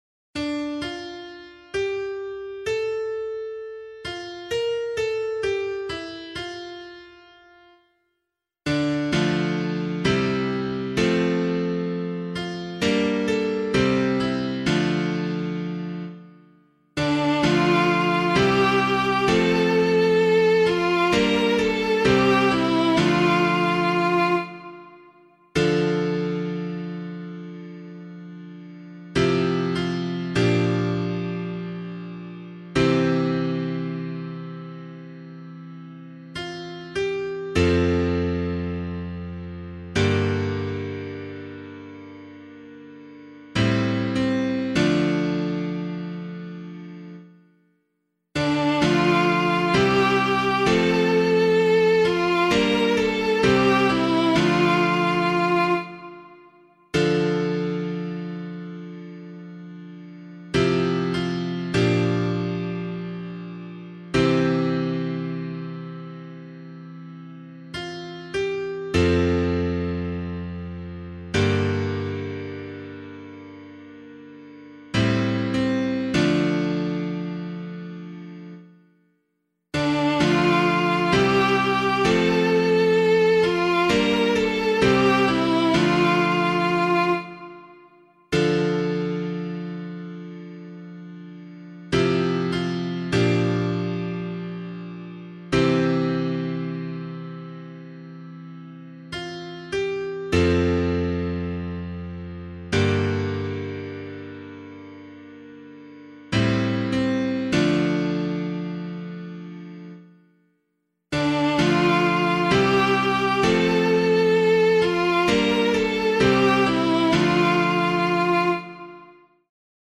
pianovocal
068 Christ the King Psalm B [APC - LiturgyShare + Meinrad 1] - piano.mp3